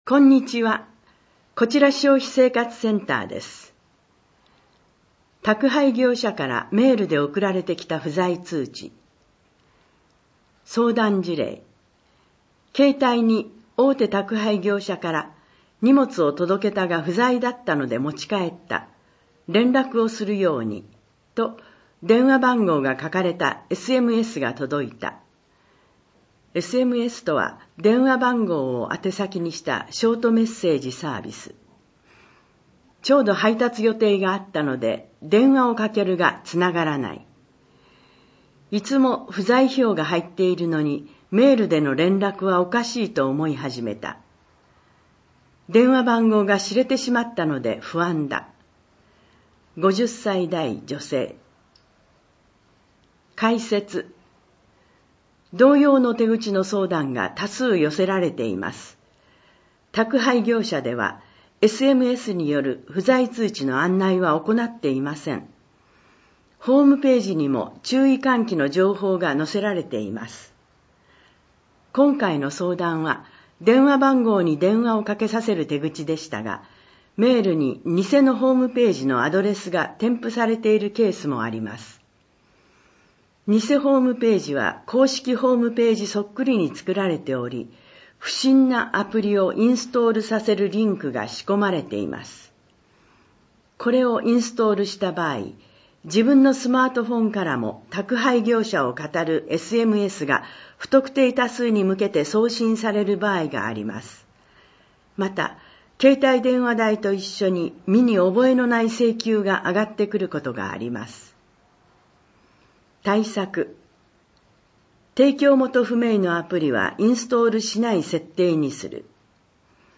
広報こうし平成31年3月号 音訳版